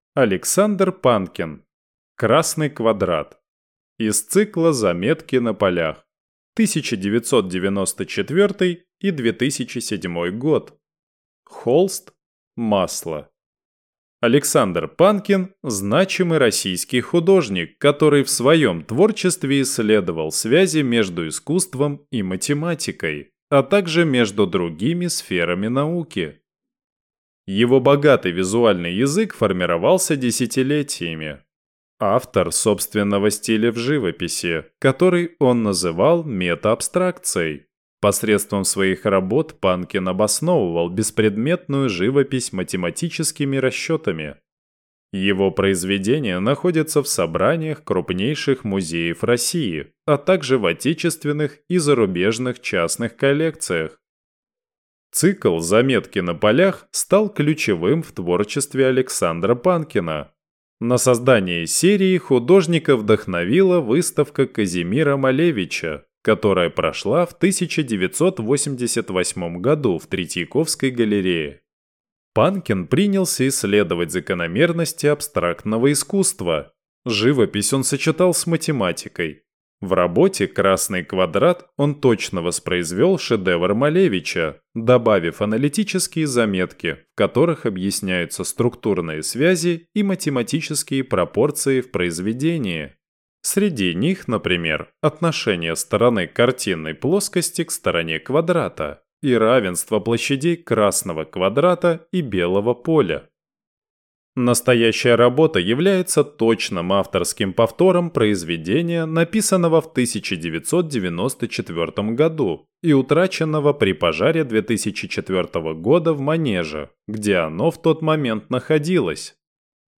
Тифлокомментарий к картине Александра Панкина "Красный квадрат"